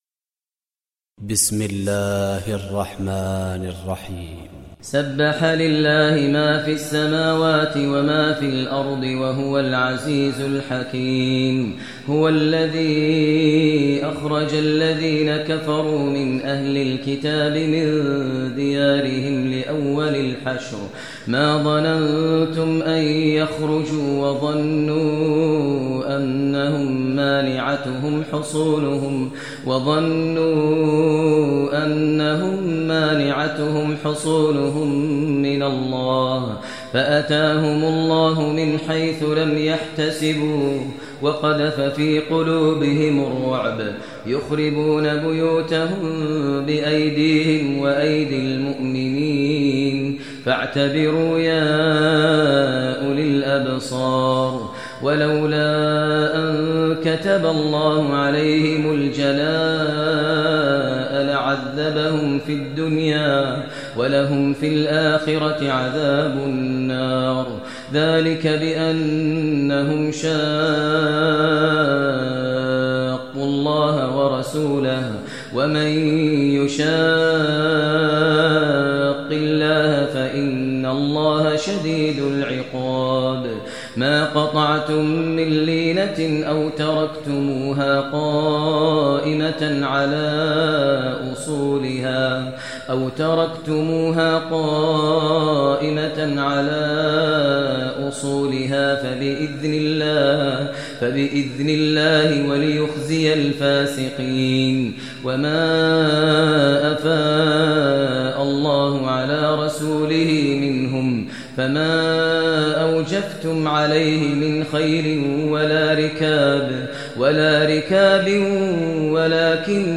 Surah Hashr Recitation by Sheikh Maher Mueaqly
Surah Hashr, listen online mp3 tilawat / recitation in Arabic recited by Imam e Kaaba Sheikh Maher al Mueaqly.